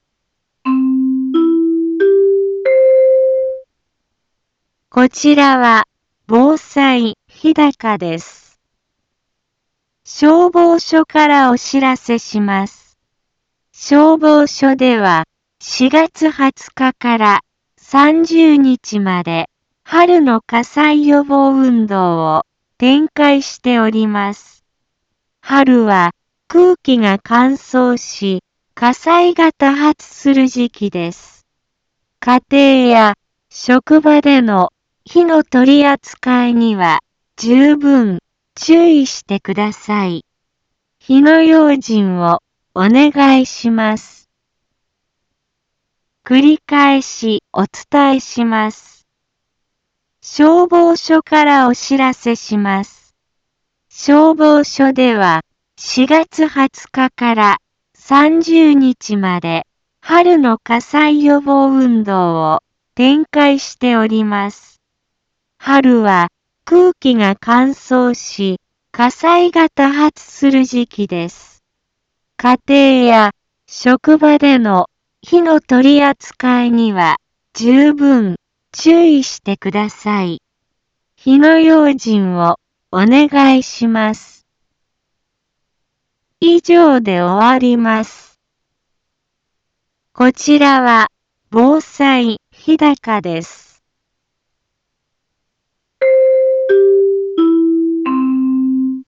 一般放送情報
Back Home 一般放送情報 音声放送 再生 一般放送情報 登録日時：2022-04-20 10:03:29 タイトル：春の火災予防運動について インフォメーション：こちらは防災日高です。